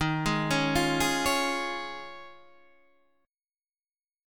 D# 7th Suspended 2nd Suspended 4th